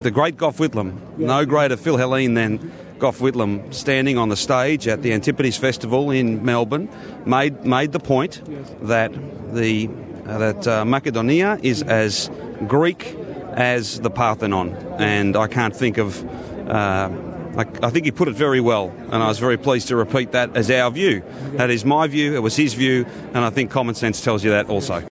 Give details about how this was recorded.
Thousands of students and representatives of Greek brotherhoods and organisations took part in a parade at the Shrine of Remembrance in Melbourne to commemorate Greek National Day.